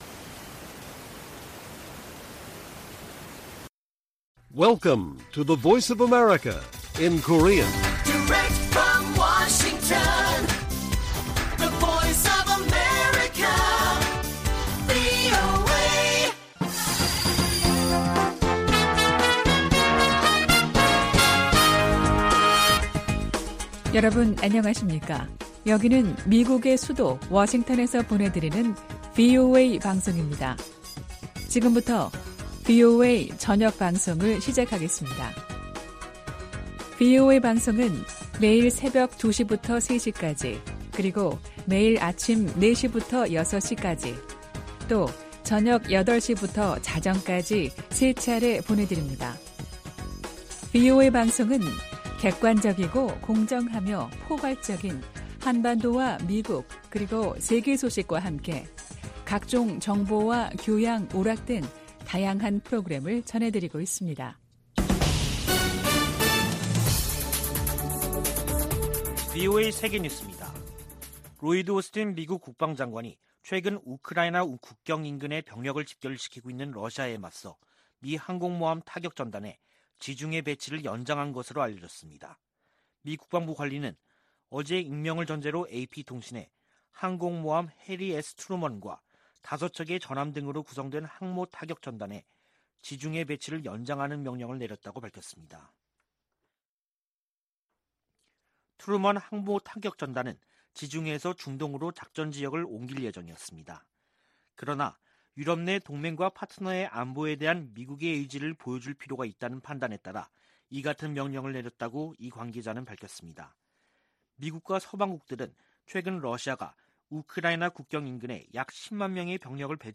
VOA 한국어 간판 뉴스 프로그램 '뉴스 투데이', 2021년 12월 29일 1부 방송입니다. 미 국무부는 한반도의 항구적 평화 달성을 위해 북한과의 외교에 전념하고 있다고 밝혔습니다. 정의용 한국 외교부 장관은 미-한 간 종전선언 문안 조율이 마무리됐다고 밝혔습니다. 조 바이든 미국 대통령이 역대 최대 규모 국방예산을 담은 2022국방수권법안에 서명했습니다.